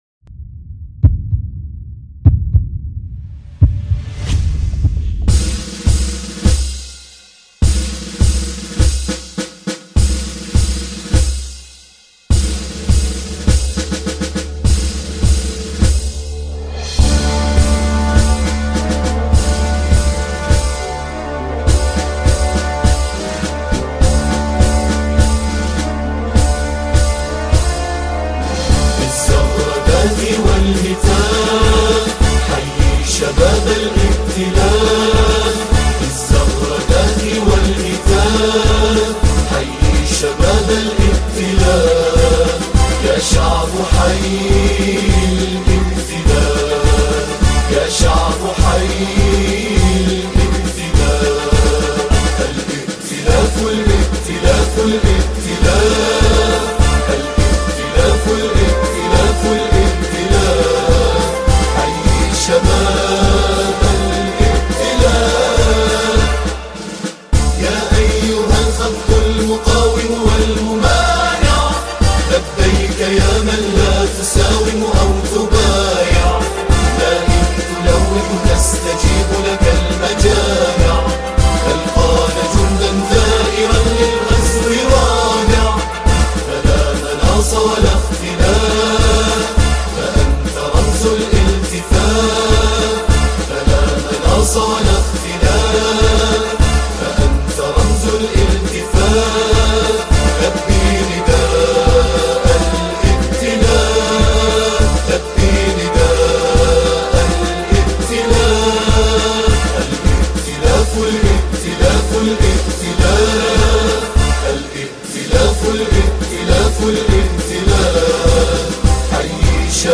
أناشيد بحرينية